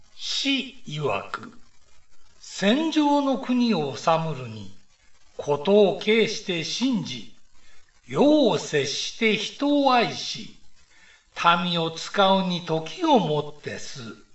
下の ＜朗読音声＞ をクリック又はタップすると、朗読音声が流れます。